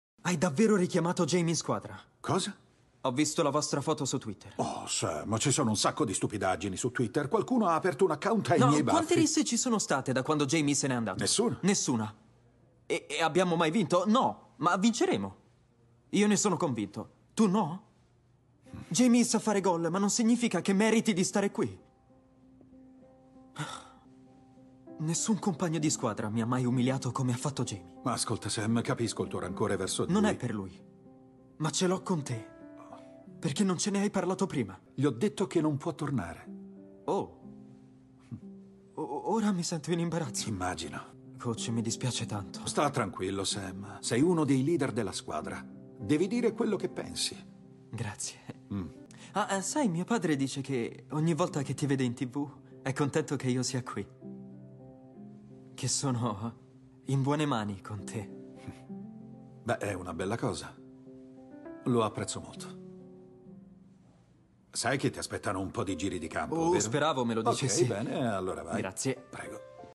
nel telefilm "Ted Lasso", in cui doppia Toheeb Jimoh.